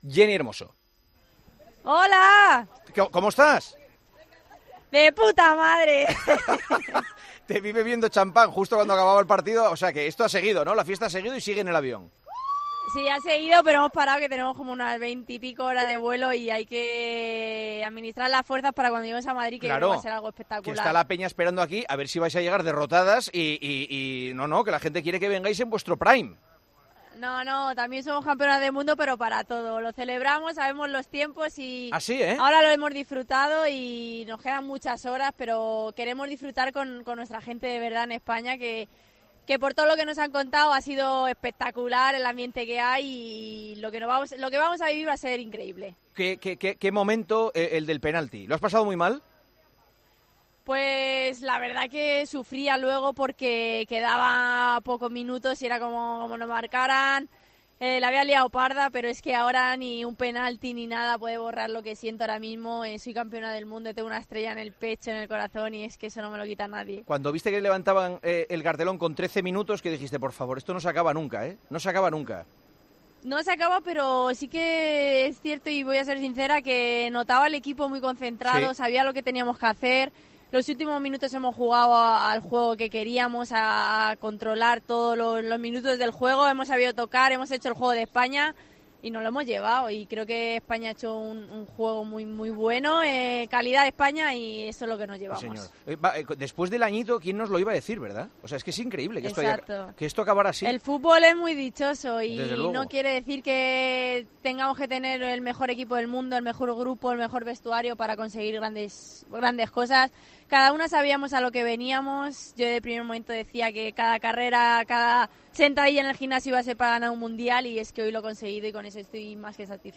Jenni Hermoso ha hablado, en Tiempo de Juego, con Juanma Castaño tras ganar el Mundial femenino.
Con Paco González, Manolo Lama y Juanma Castaño